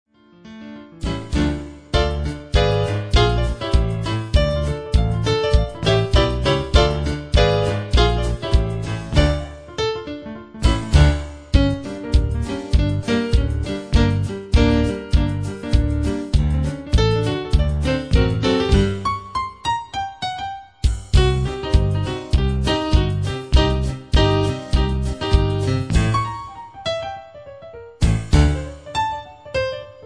Fox-trot for shoes with noisy hills.